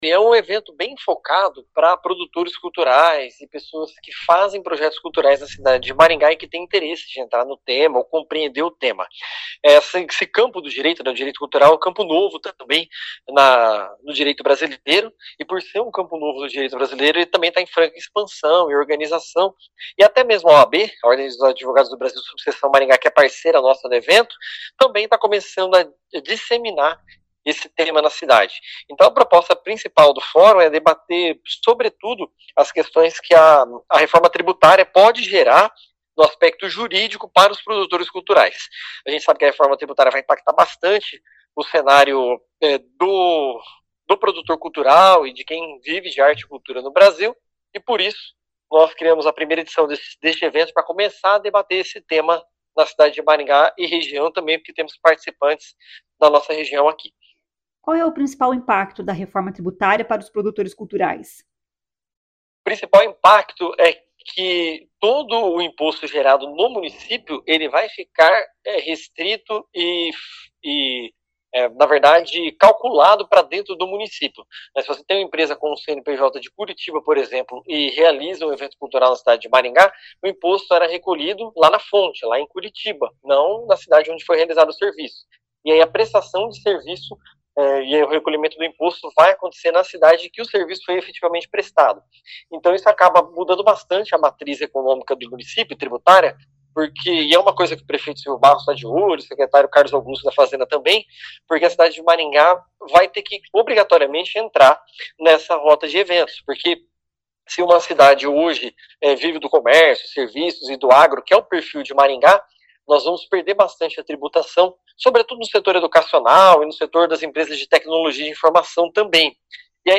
O secretário municipal de Cultura, Tiago Valenciano, explica que o objetivo é orientar produtores culturais sobre os impactos provocados pela Reforma Tributária no setor.